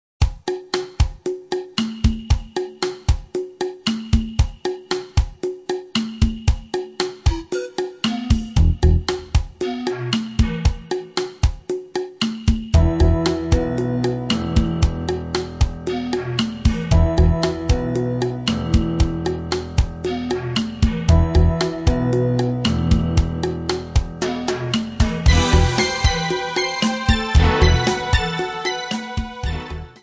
guitar melodies from Poland